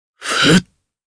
Kain-Vox_Casting1_jp.wav